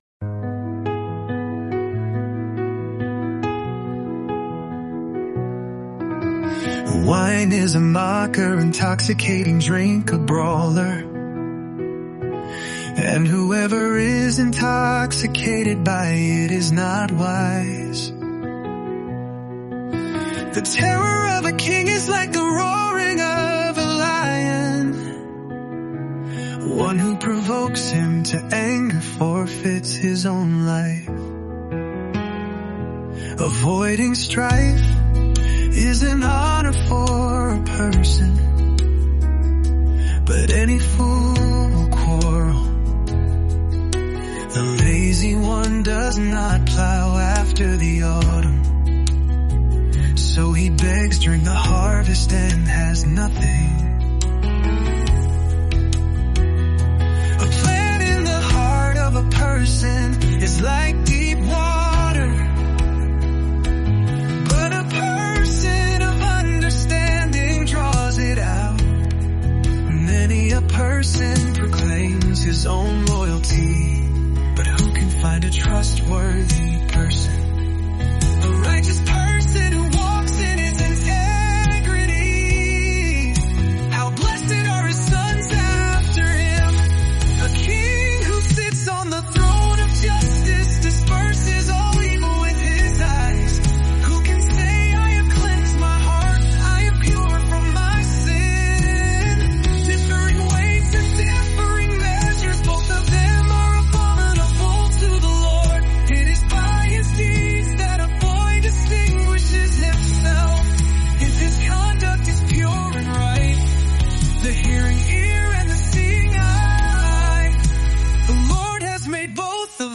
Immerse yourself in the timeless wisdom of Proverbs in just 31 days through word-for-word Scripture songs. Each day, listen to passages that offer guidance for daily life—covering wisdom, integrity, relationships, work, and the fear of the Lord—brought to life through music.